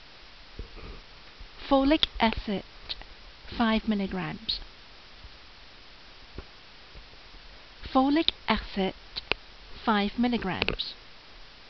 Pronunciation[edit]